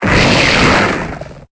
Cri de Golgopathe dans Pokémon Épée et Bouclier.